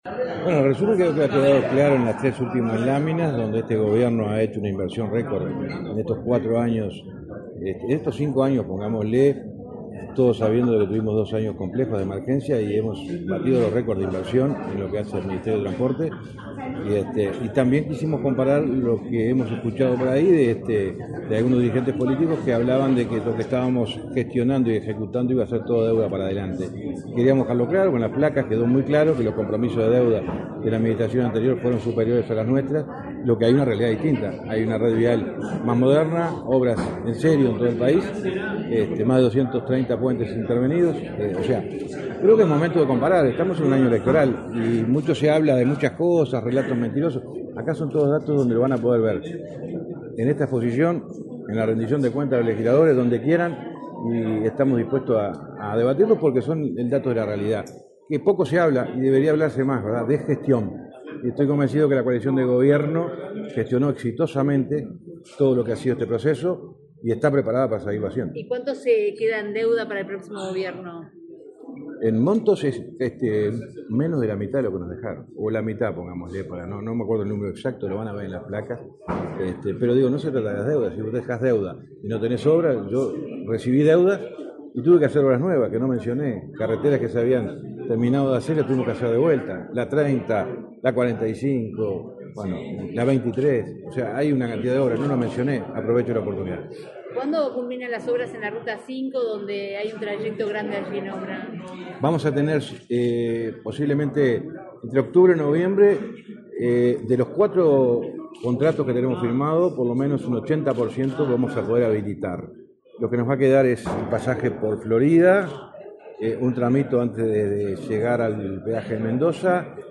Declaraciones del ministro de Transporte, José Luis Falero
Declaraciones del ministro de Transporte, José Luis Falero 01/08/2024 Compartir Facebook X Copiar enlace WhatsApp LinkedIn El ministro de Transporte, José Luis Falero, disertó, este jueves 1:° en Montevideo, en un almuerzo de trabajo de la Asociación de Dirigentes de Marketing.